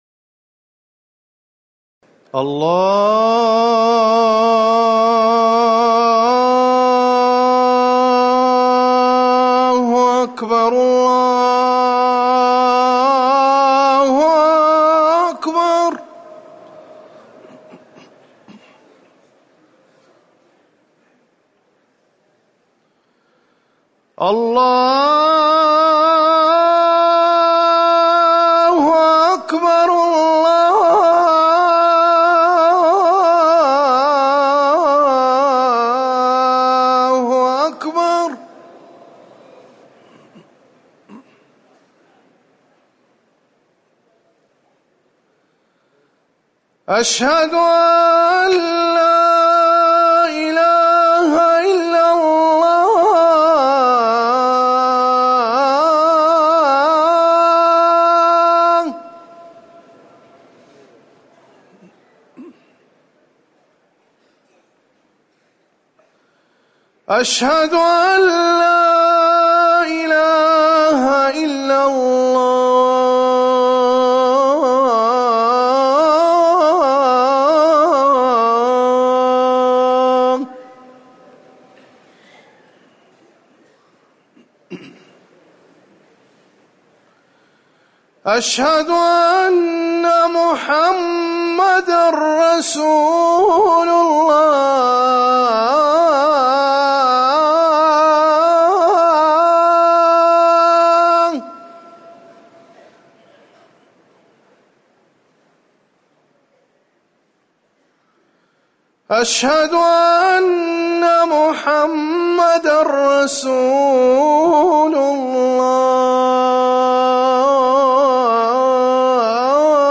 أذان الظهر
تاريخ النشر ٣ محرم ١٤٤١ هـ المكان: المسجد النبوي الشيخ